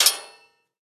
assemblerStrike2.ogg